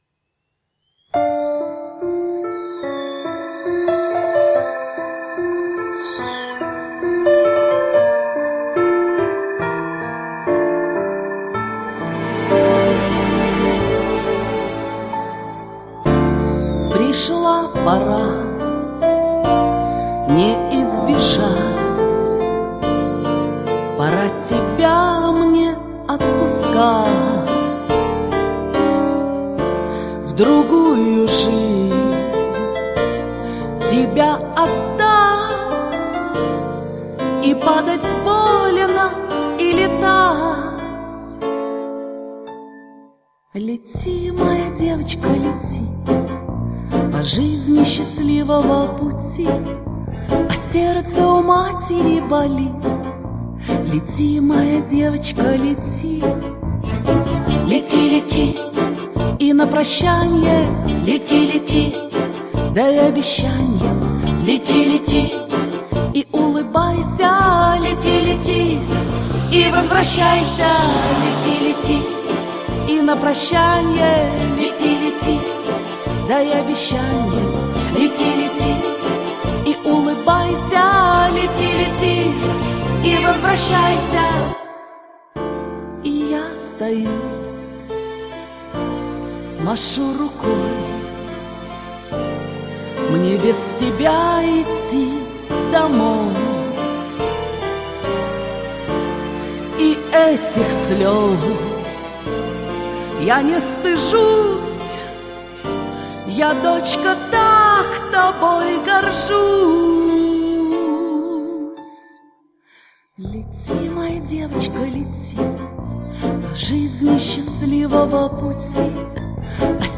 радует теплый, светлый голос- хорош для колыбельных